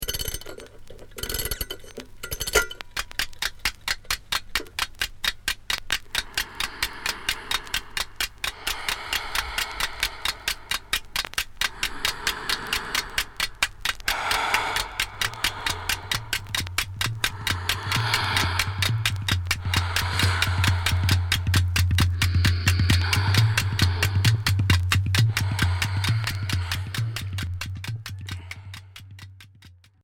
Percus groove barré Deuxième 45t retour à l'accueil